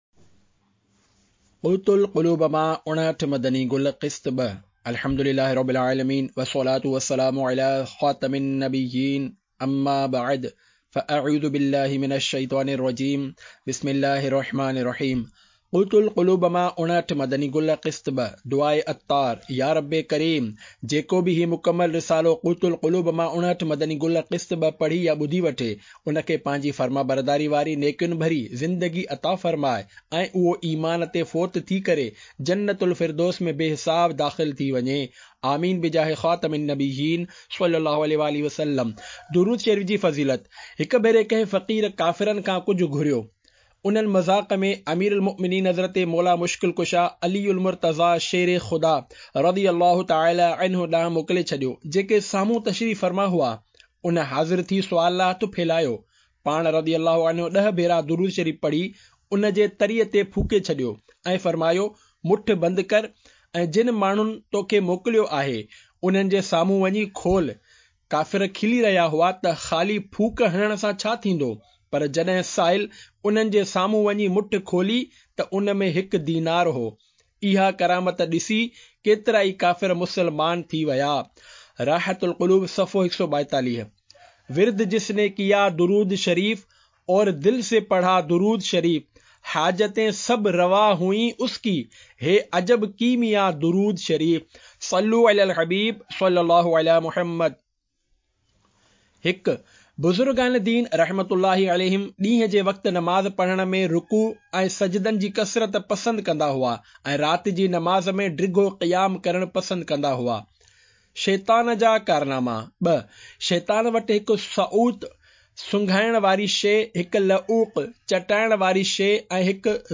Audiobook - Qut Al Qulub se 59 Madani Phool Qist 2 (Sindhi)